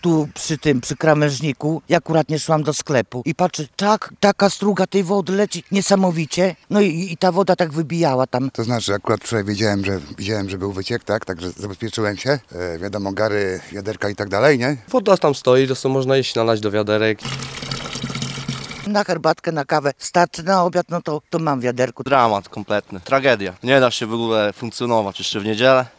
ZWiK zapewnił mieszkańcom dostęp do wody poprzez beczkowóz, jednak jak nam mówią mieszkańcy – życie nadal jest utrudnione: